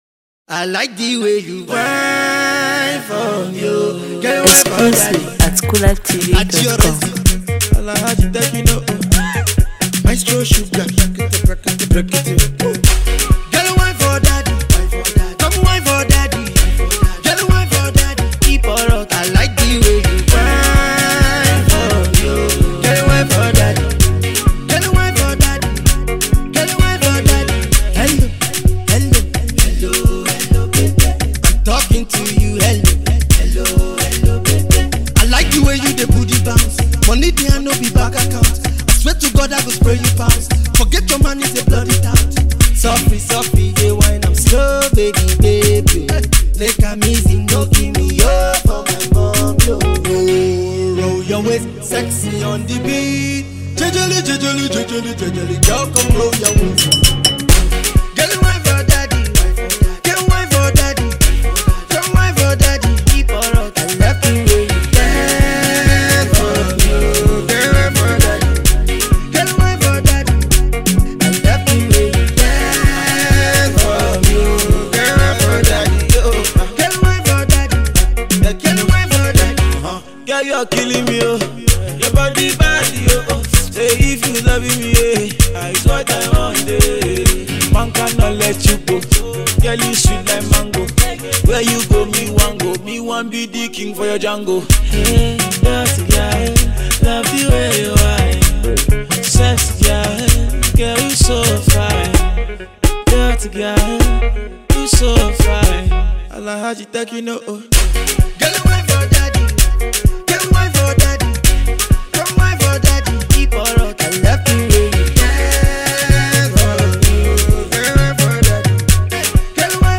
aimed at making people dance